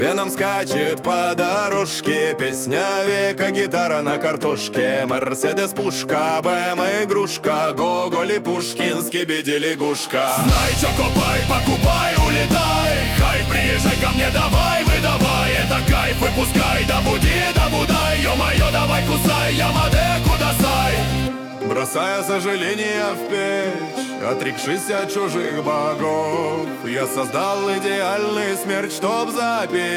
Жанр: Рок / Русские
Rock in Russian